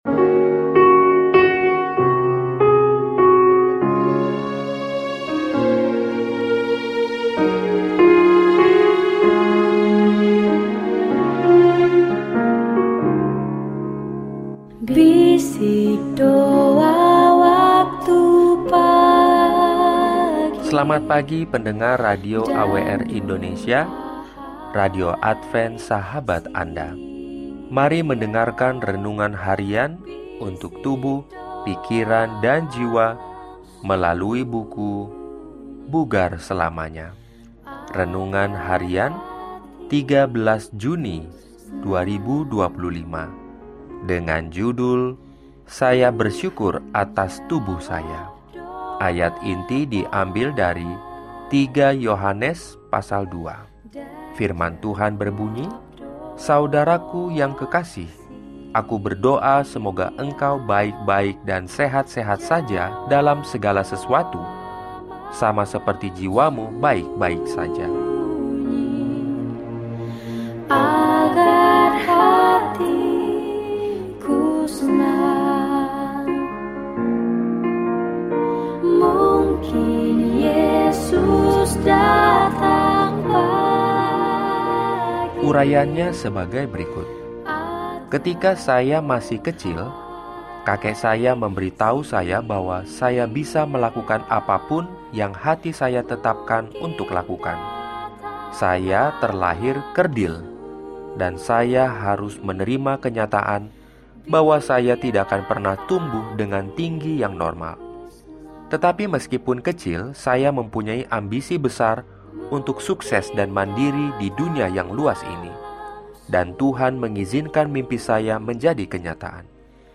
Daily Spiritual Devotional in Indonesian from Adventist World Radio